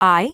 OCEFIAudio_en_LetterI.wav